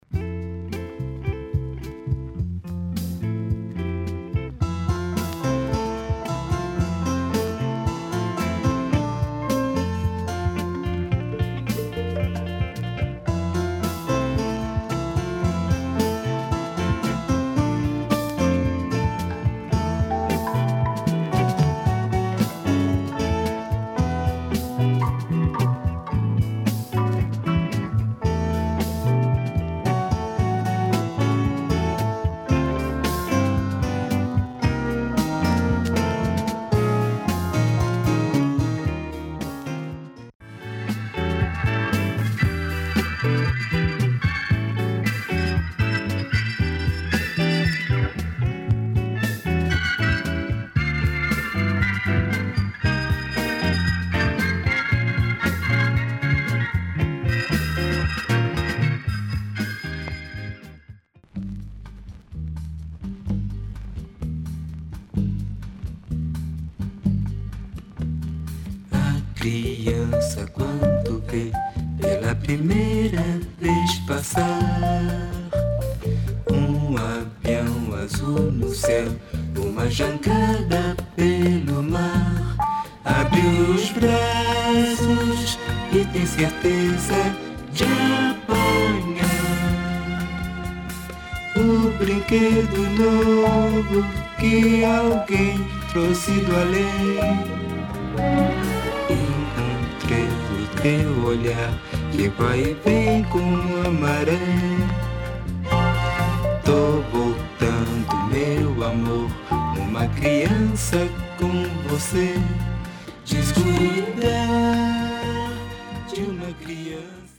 Nice easy groove
plus a lovely bossa
Soundtracks